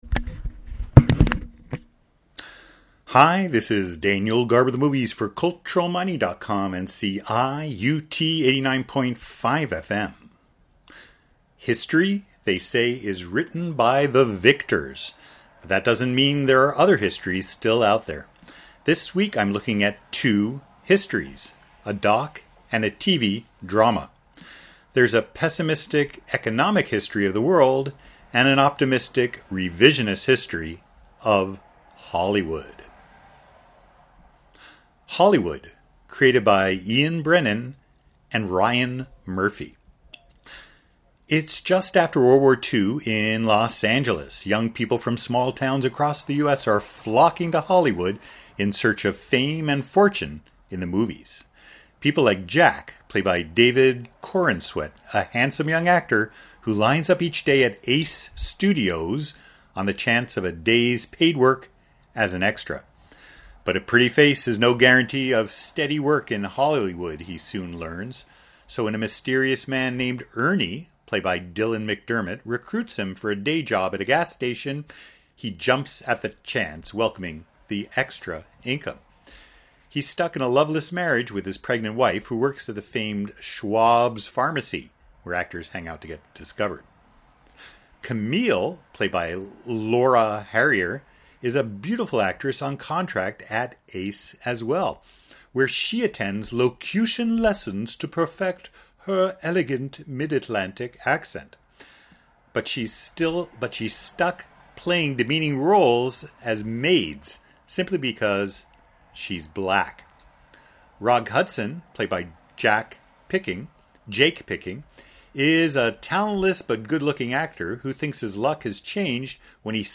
(Home recording, no music) Hi